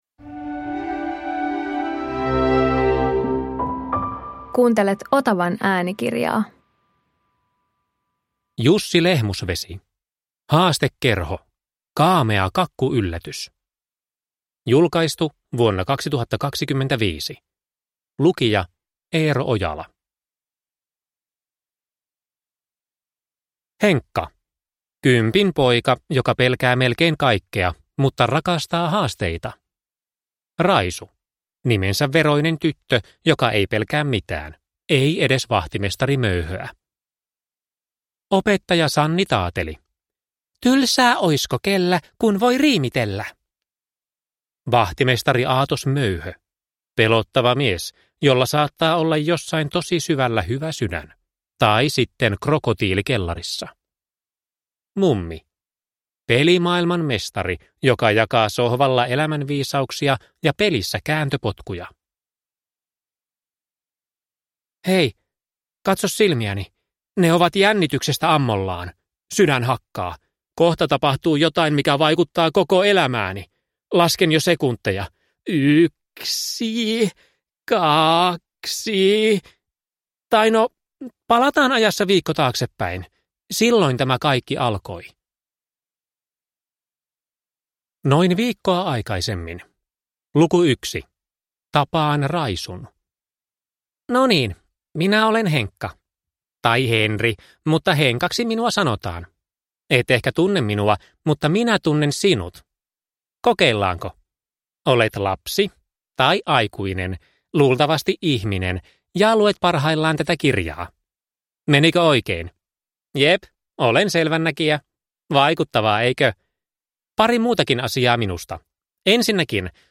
Haastekerho – Kaamea kakkuyllätys – Ljudbok